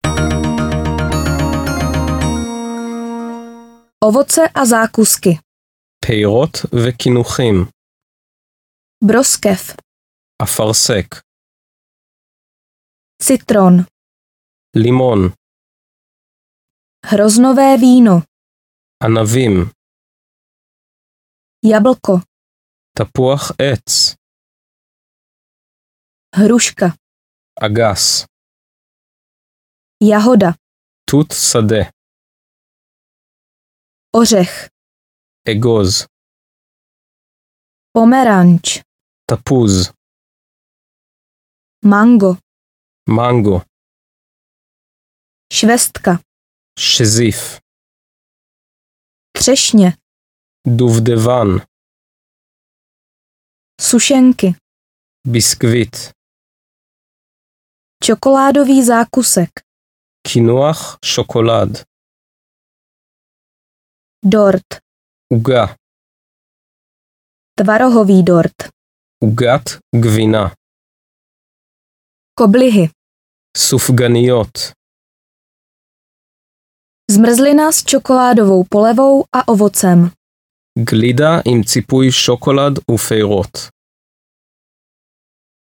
Ukázka z knihy
Obsahuje 32 témat k snadnému dorozumnění, více než 500 konverzačních obratů s výslovností, samostudium formou poslechu a opakování. Dále dvojjazyčnou nahrávku rodilých mluvčích a základní informace pro turisty.Obsah: Výslovnost Všeobecné výrazy Pozdravy a představování Otázky a všeobecné dotazy Slova opačná Hotel - ubytování Restaurace Snídaně Předkrmy Polévky Maso Ryby a plody moře Přílohy Zelenina a saláty Ovoce a zákusky Nápoje Stížnosti Cestování vlakem Cestování letadlem Cestování lodí   Cestování městskou dopravou Taxi Půjčovna aut Prohlížení pamětihodností Zábava a kultura Obchody a služby Banka Pošta Telefon Datum, čas, počasí Čísla Pohotovost